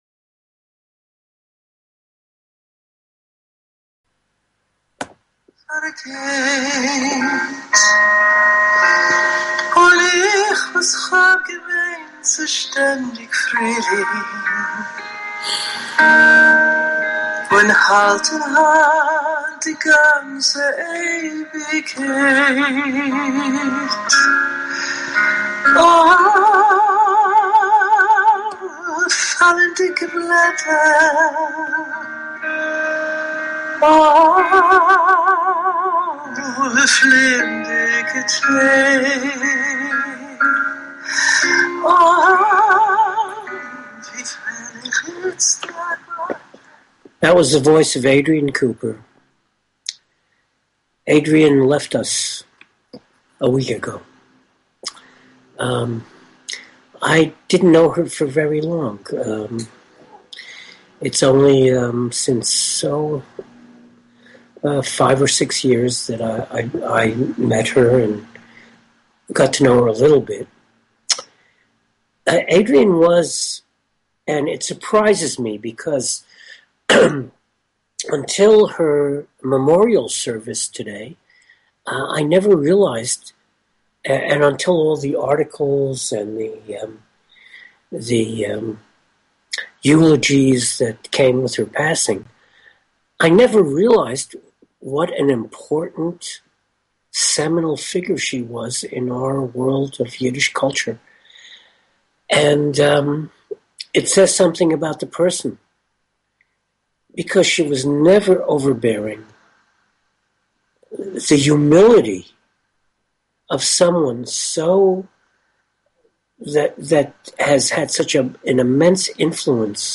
Talk Show Episode, Audio Podcast, New_Yiddish_Rep_Radio_Hour and Courtesy of BBS Radio on , show guests , about , categorized as
A forum for Yiddish Culture on internet radio. Talk radio in Yiddish, in English, sometimes a mix of both, always informative and entertaining. NYR Radio hour will bring you interviews with Yiddish artists, panel discussions, radio plays, comedy, pathos, and a bulletin board of events, as well as opinions and comments from listeners.